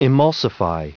Prononciation du mot emulsify en anglais (fichier audio)
Prononciation du mot : emulsify